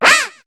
Cri de Gruikui dans Pokémon HOME.